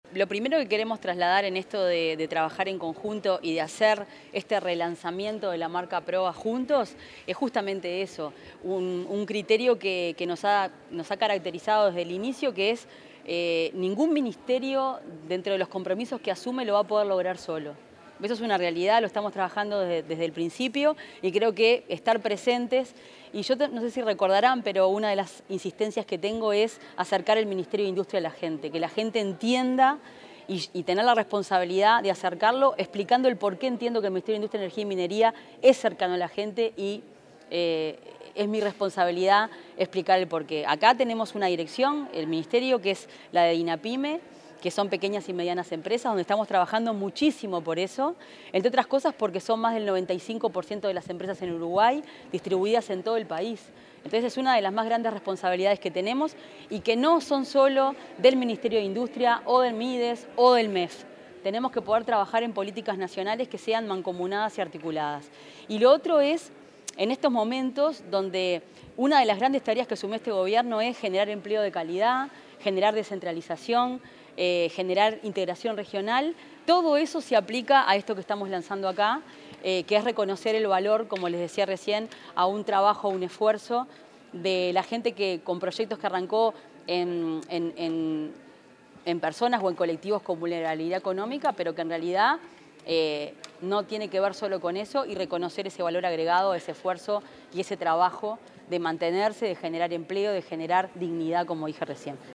Declaraciones de la ministra de Industria, Energía y Minería, Fernanda Cardona
Declaraciones de la ministra de Industria, Energía y Minería, Fernanda Cardona 26/08/2025 Compartir Facebook X Copiar enlace WhatsApp LinkedIn Tras la presentación del proceso Hacia una Estrategia Nacional de Productos con Valor Social (Provas) 2025-2030, la ministra de Industria, Energía y Minería, Fernanda Cardona, dialogó con los medios periodísticos.